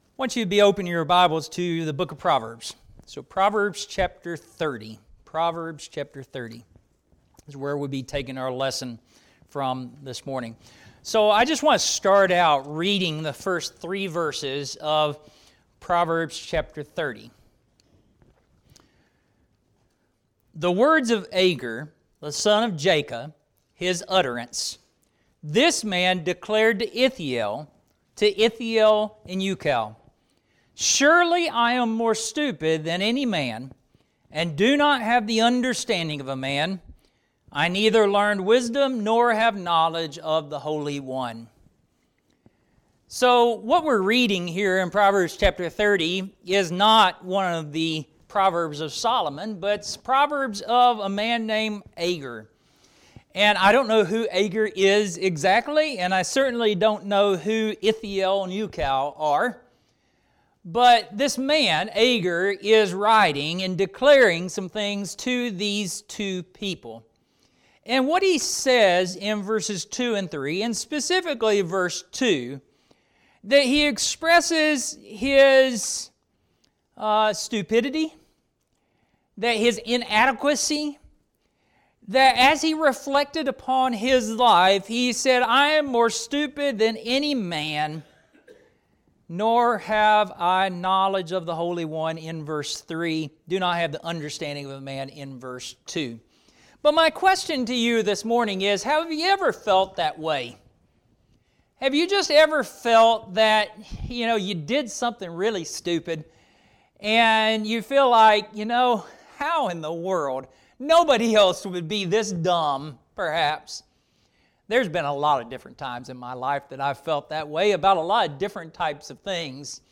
Gospel Meeting